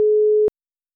busy.wav